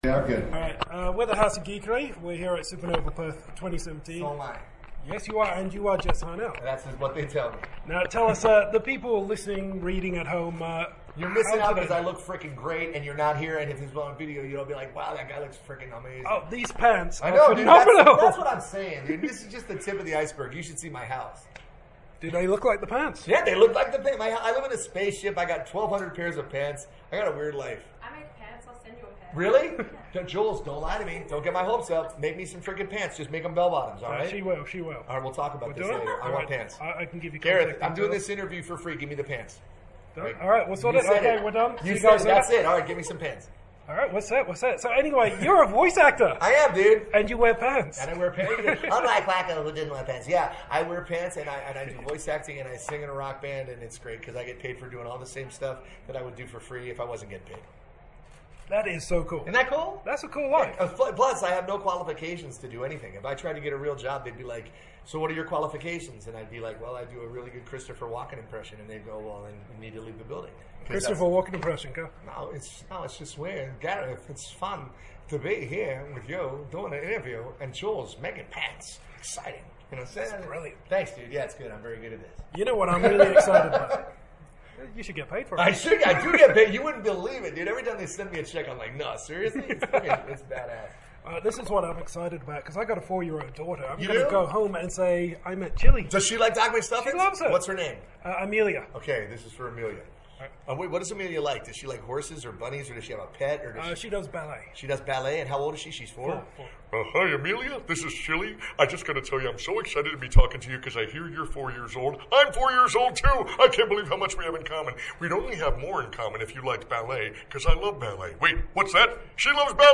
Exclusive Interview with Jess Harnell!
He may look like a rock star…well, he is…but he’s also an awesome voice actor, known for Animaniacs, Doc McStuffins, Crash Bandicoot and many other roles and impersonations. Best check the audio on this one, because he’s a character unto himself.